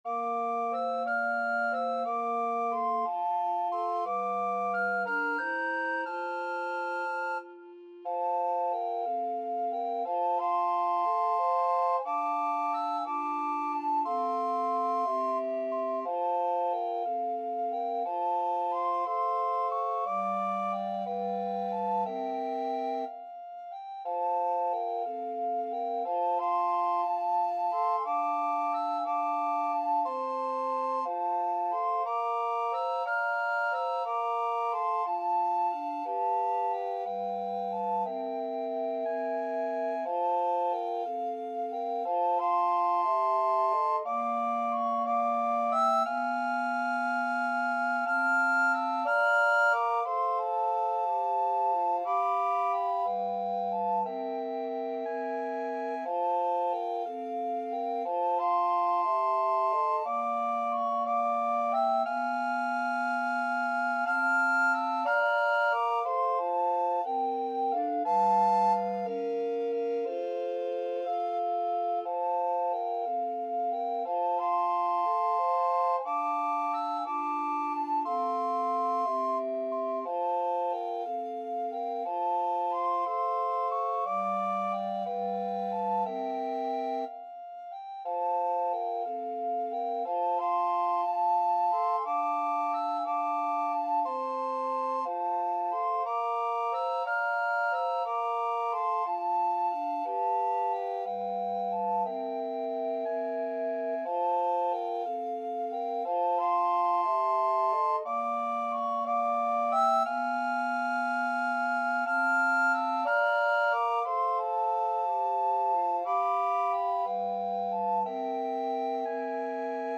Soprano RecorderAlto RecorderTenor RecorderBass Recorder
6/8 (View more 6/8 Music)
Gently and with expression . = c. 60
Traditional (View more Traditional Recorder Quartet Music)